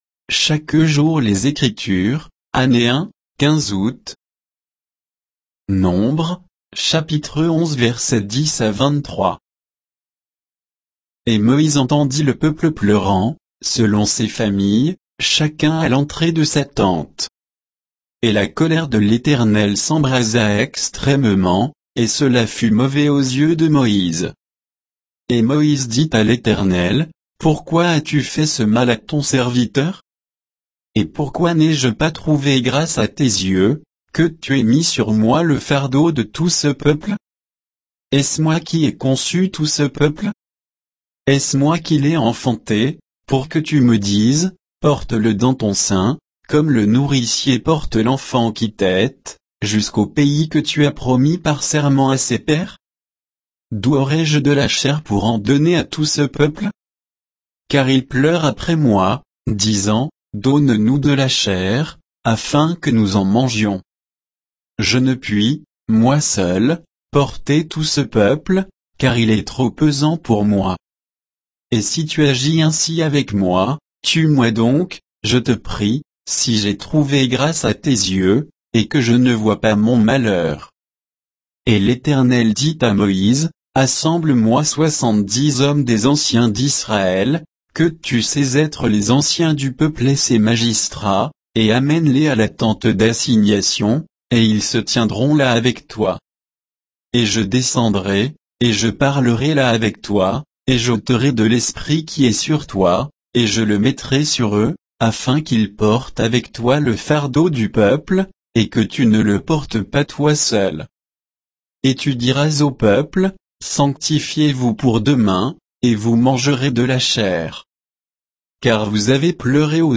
Méditation quoditienne de Chaque jour les Écritures sur Nombres 11